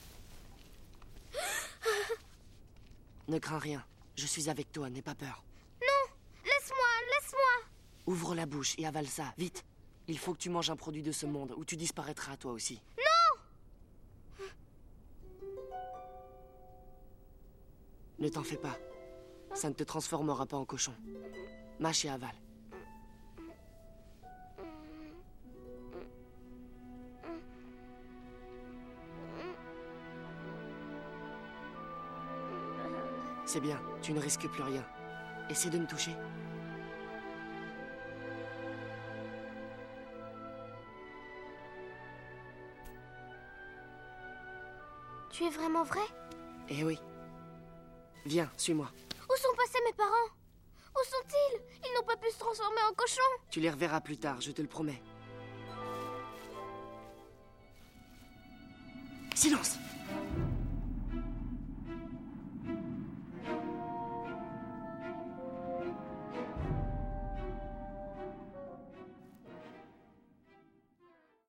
Un jeune garçon apparaît, qui semble vouloir aider Chihiro. Le dialogue nous apprend que l’on peut disparaître de ce monde ou s’y transformer en cochon : nous basculons dans le fantastique.
6.-Dialogue-Chihiro-Haku-VF.mp3